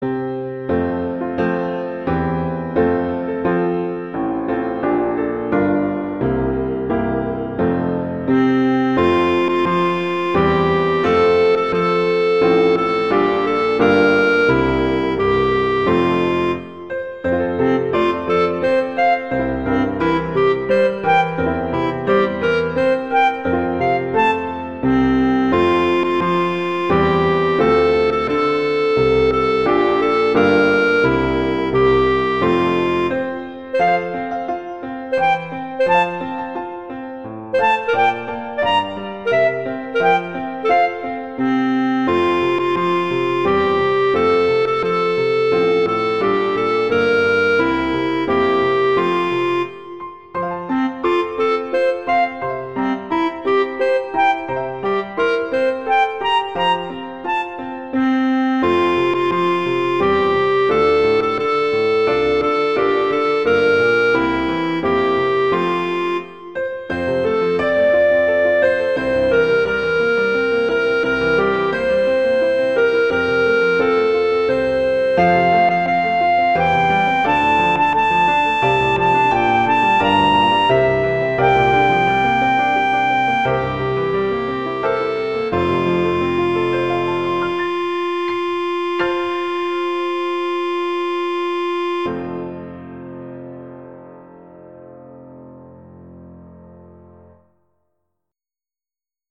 arrangements for clarinet and piano
clarinet and piano